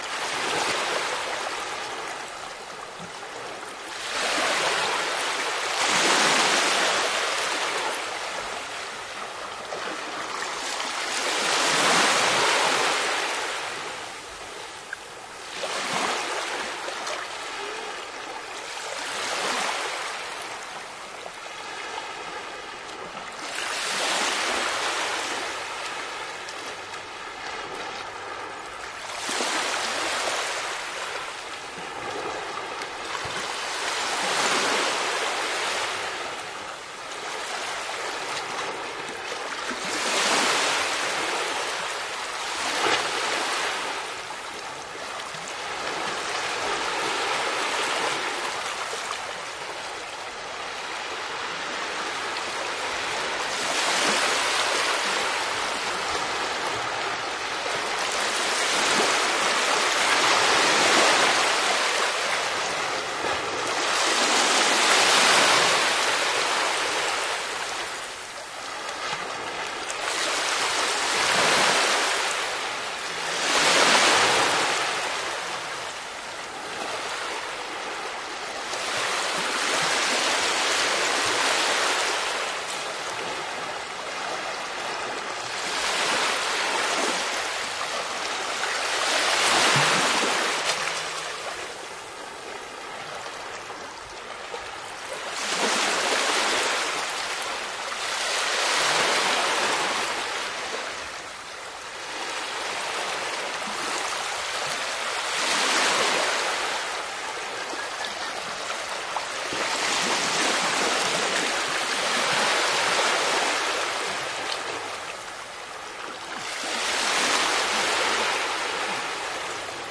Sedaye Amvaje Darya.mp3
Sedaye-Amvaje-Darya.mp3